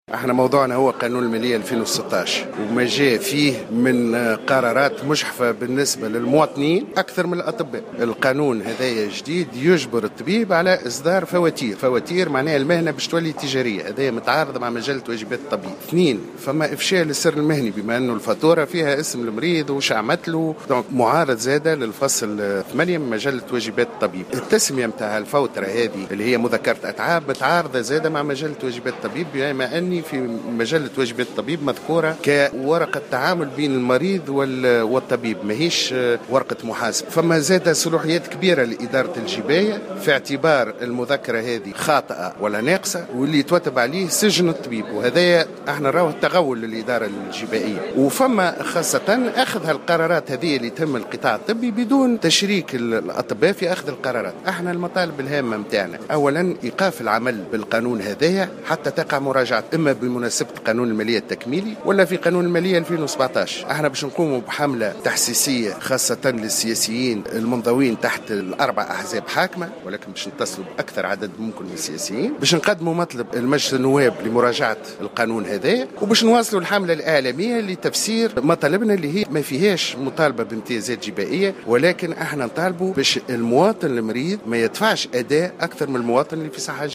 خلال اجتماع خارق للعادة لتنسيقية الهياكل الطبية بقصر المؤتمرات بالعاصمة